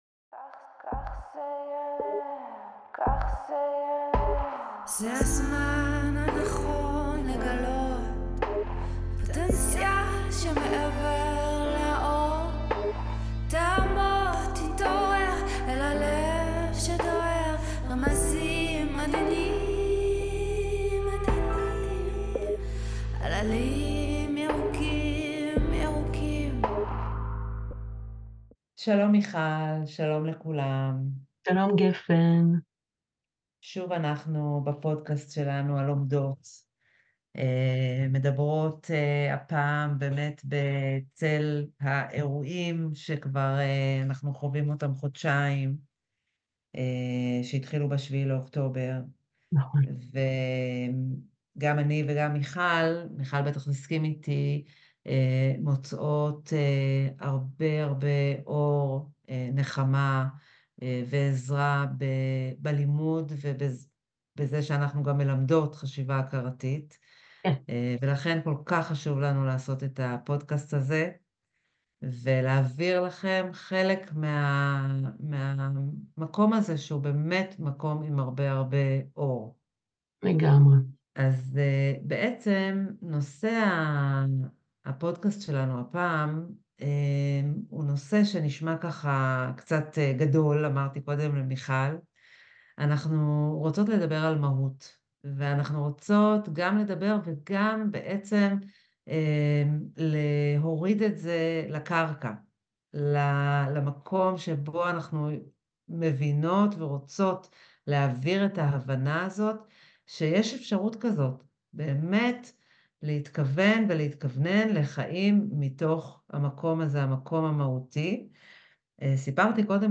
שיחה